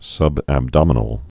(sŭbăb-dŏmə-nəl)